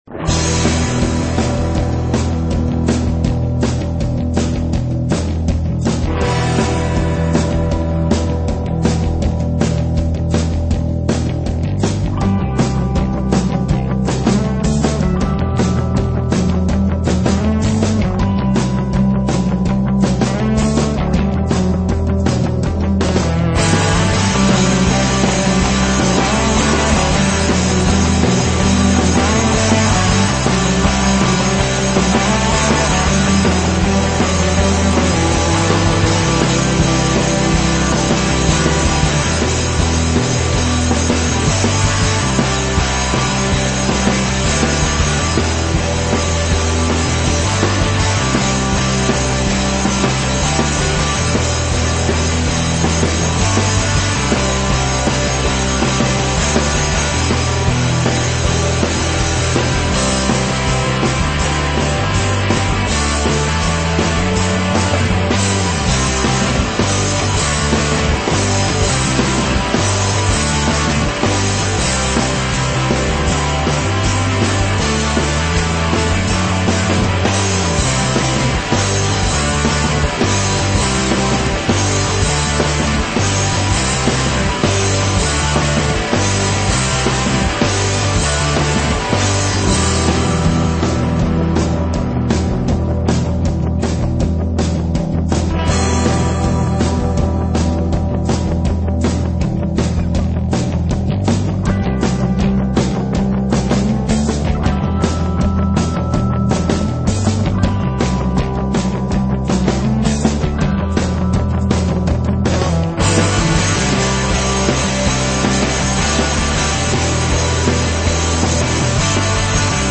rock
metal
hard rock
punk
high energy rock and roll